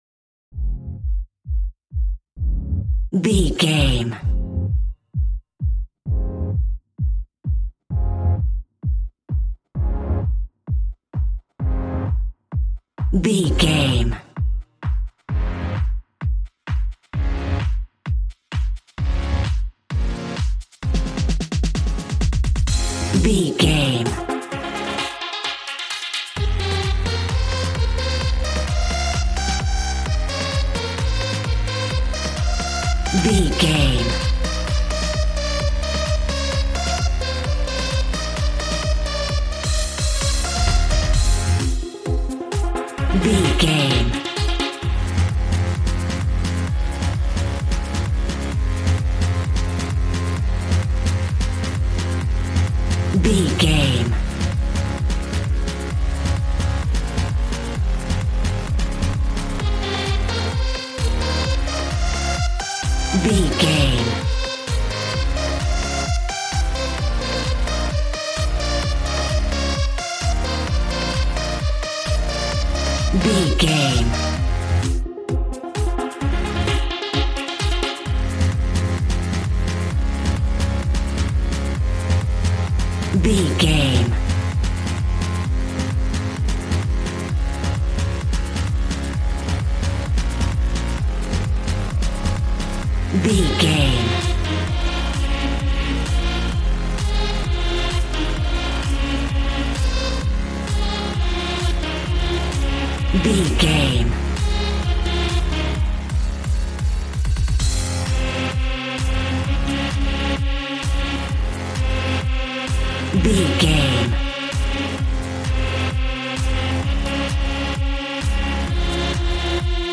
Aeolian/Minor
Fast
groovy
energetic
synthesiser
drums